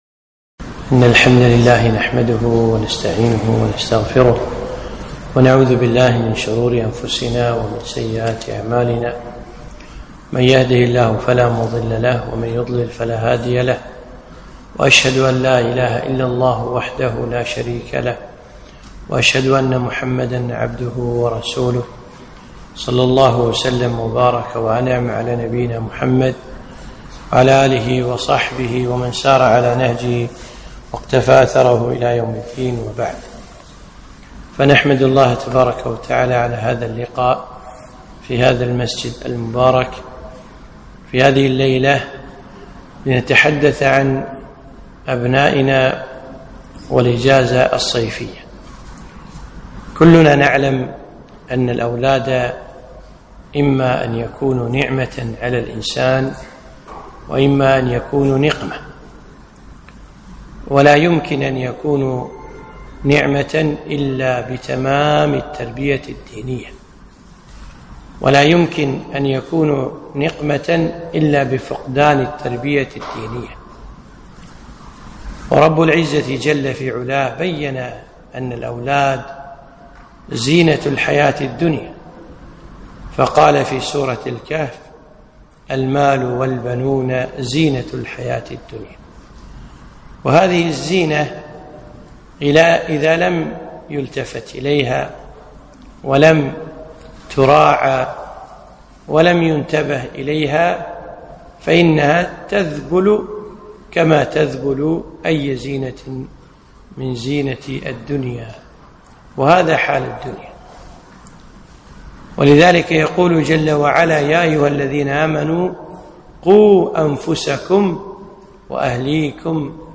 محاضرة - أبناؤنا واغتنام الإجازة